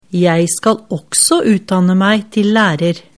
setningsdiktat_skolesystemet05.mp3